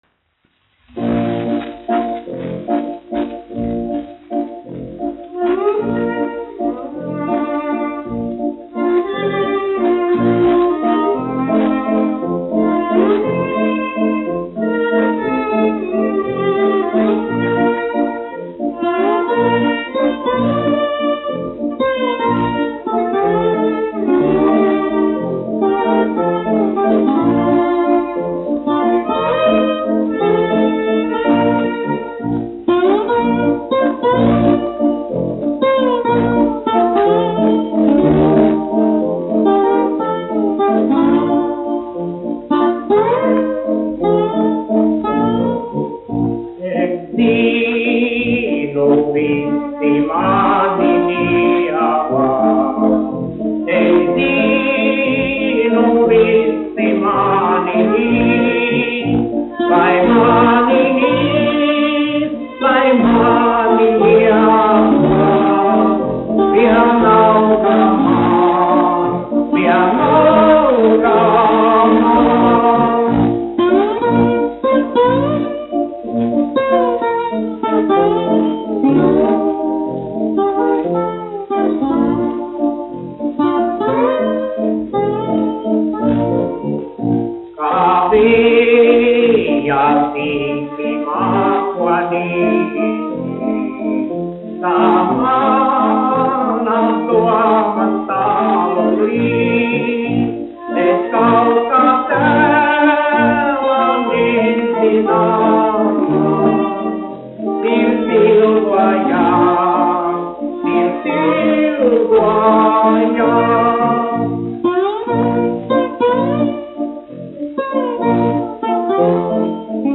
1 skpl. : analogs, 78 apgr/min, mono ; 25 cm
Populārā mūzika
Valši
Latvijas vēsturiskie šellaka skaņuplašu ieraksti (Kolekcija)